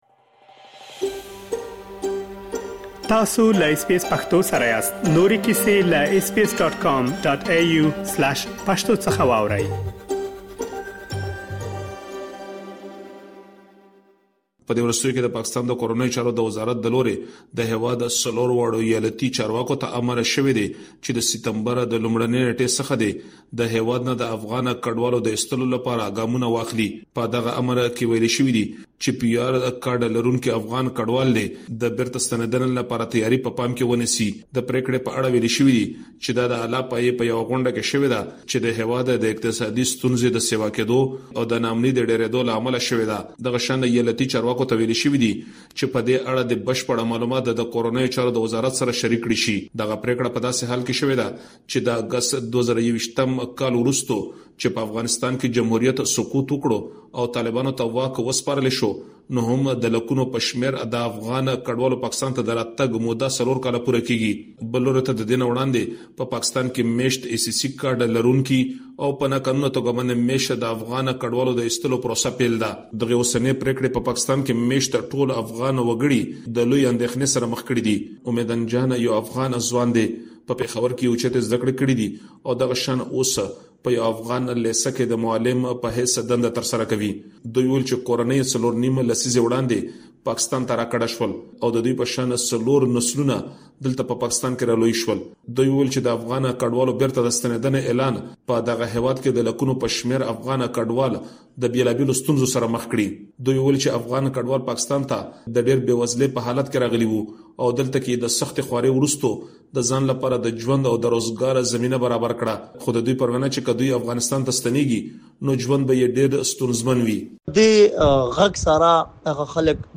له خیبر پښتونخوا څخه یو رپوټ را استولی دی